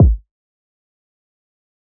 Metro Kicks [Coke].wav